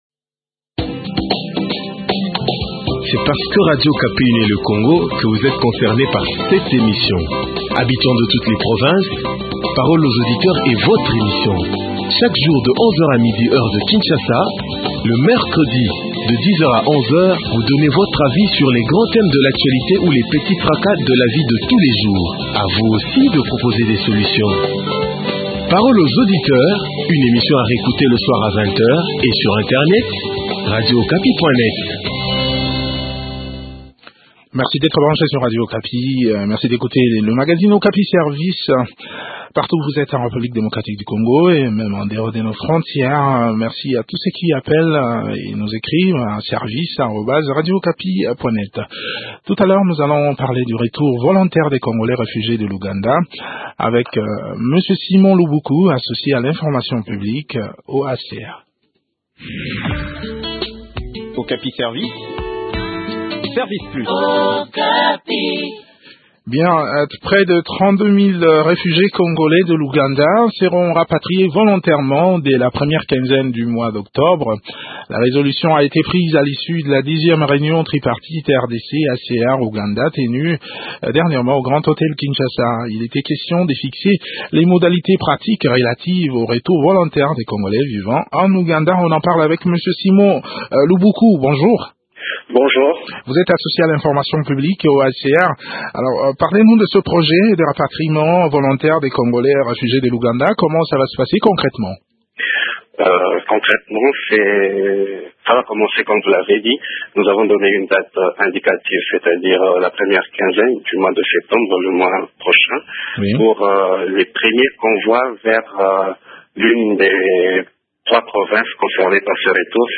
Le point sur les modalités de l’exécution de ce projet dans cet entretien